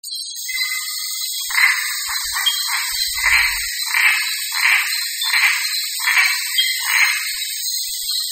chant: chante entre 5 et 20 mètres de hauteur, dans les broméliacées, audible à plusieurs dizaines de mètres:
chant osteocephalus.mp3